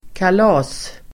Uttal: [²kal'a:s-]